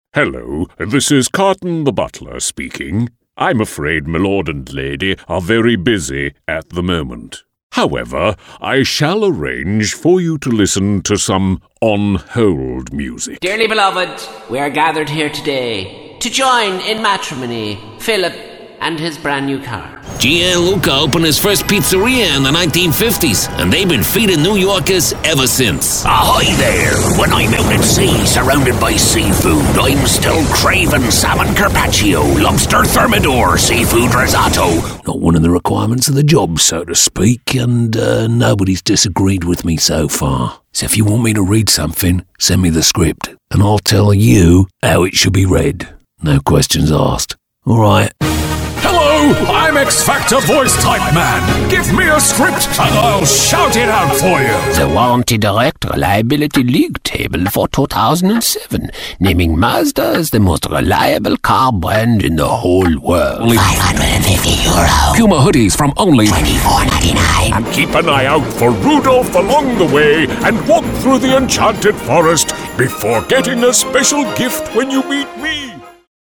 Englisch (Irland)
Tief, Natürlich, Zuverlässig, Freundlich, Warm
Telefonie